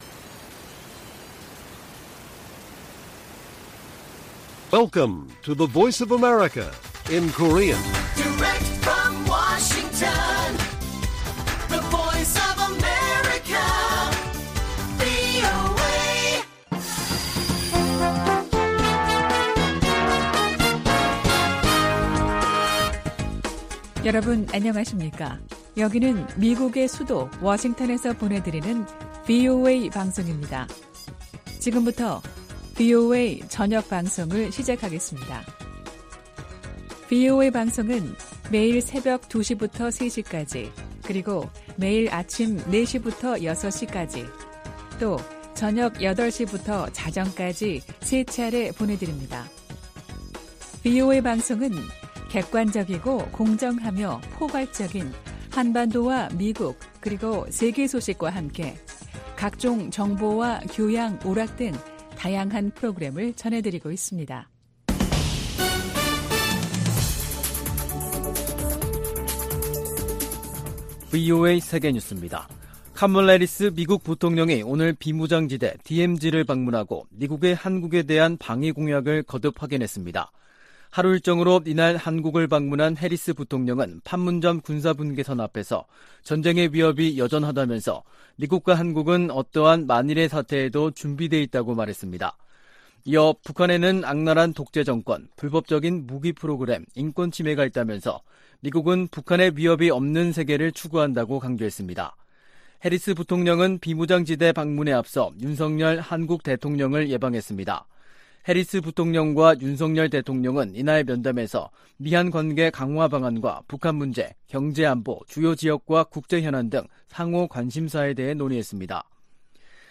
VOA 한국어 간판 뉴스 프로그램 '뉴스 투데이', 2022년 9월 29일 1부 방송입니다. 한국을 방문한 카멀라 해리스 미국 부통령이 윤석열 한국 대통령을 만나 미국의 철통같은 방위공약을 재확인했습니다. 북한이 미국 항모전단이 동해상에서 해상훈련을 하는 중에 탄도미사일을 발사한 것은 핵무력 과시와 김정은에 대해 위협을 멈추라는 메시지가 있다고 전문가들이 분석했습니다. 미 재무부 고위 당국자가 러시아와 거래하는 북한의 무기 공급책을 추적 중이라고 밝혔습니다.